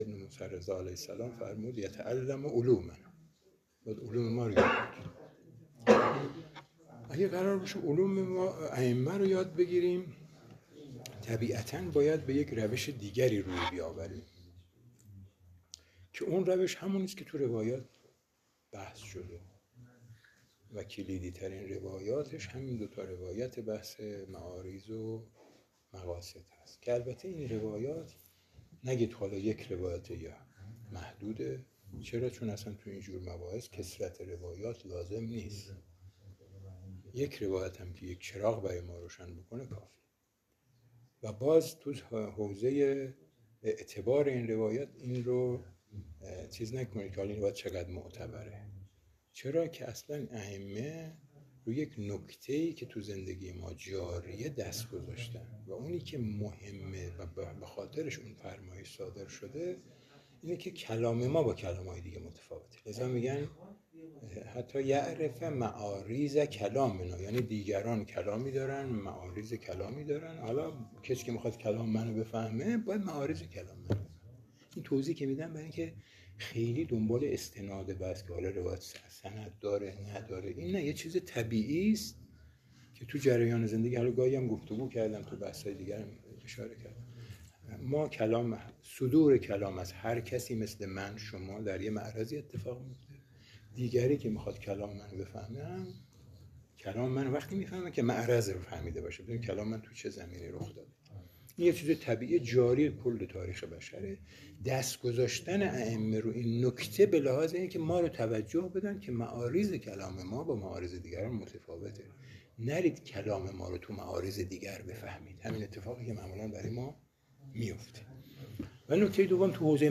نشست علمی دوازدهم
محل برگزاری: موسسه آموزش عالی اخلاق و تربیت